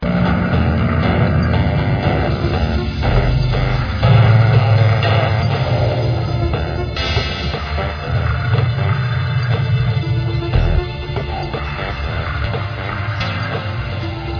Royalty Free Music for use in any type of